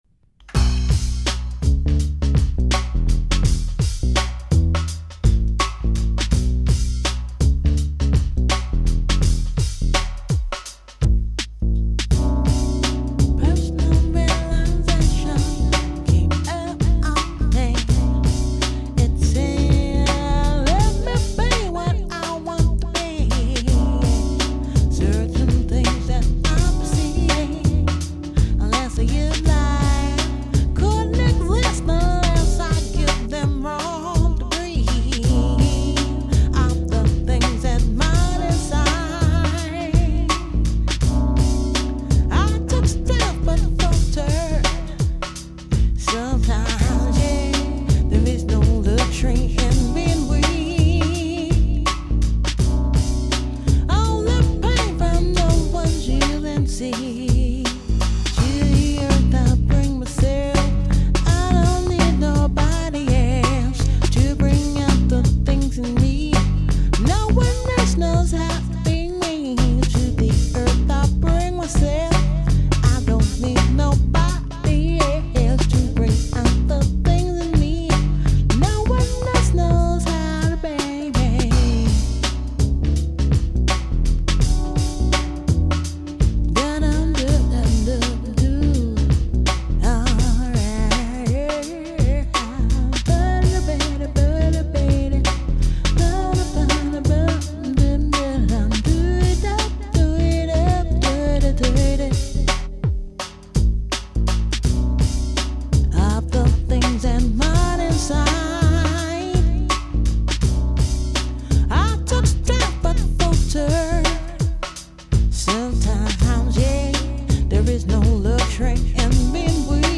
Here are some demo tapes that survived from back in the days...
Personal A late-night, studio rehersal of a song we used to do live.